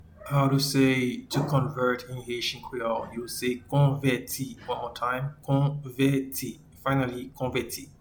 Pronunciation: